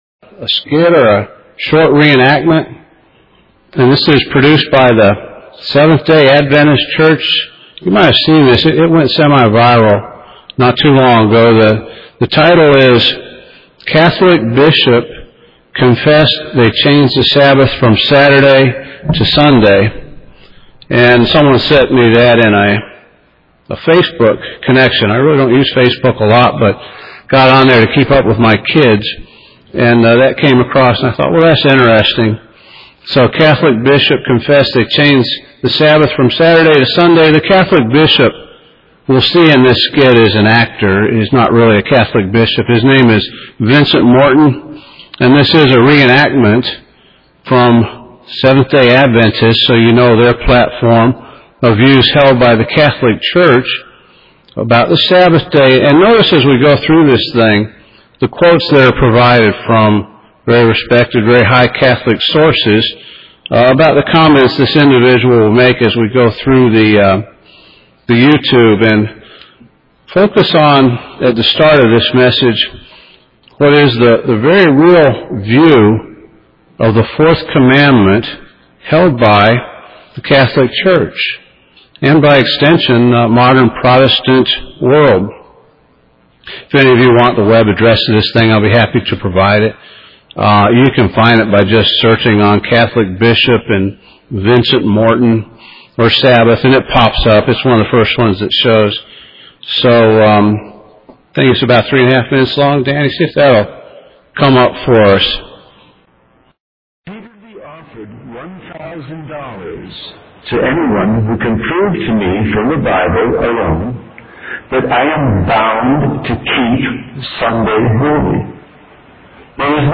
The truth of what Christ meant and how we as God's people should understand the historical and future consequences of the misunderstanding about the authority that the church has. Note - A short video at the beginning of this sermon was played. The sound quality is poor during this period, lasting about 4 minutes.